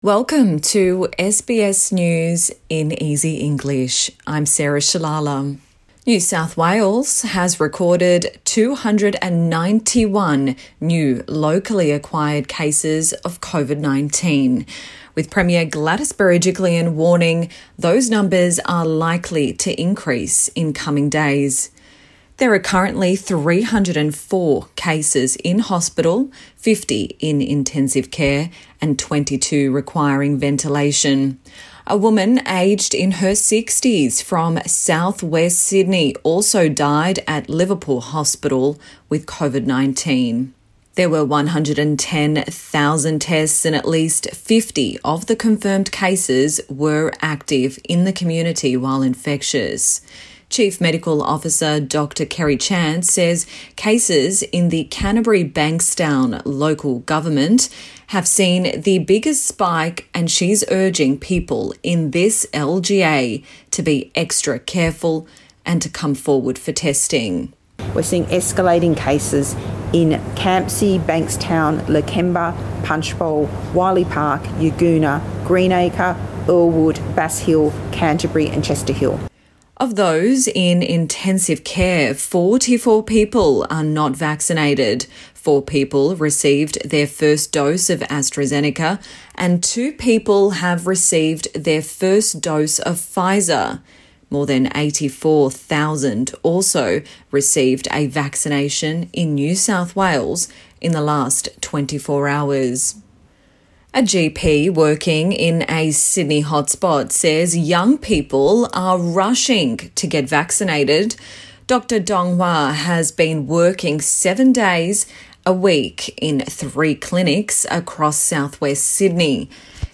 A daily 5 minute news wrap for English learners.